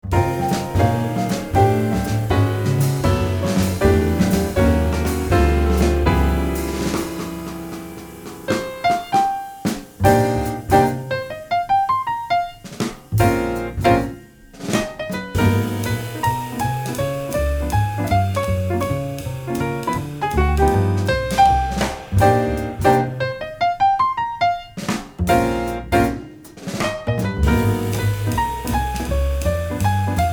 piano
contrabbasso
batteria
una vivace alternanza di piano e batteria